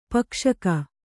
♪ pakṣaka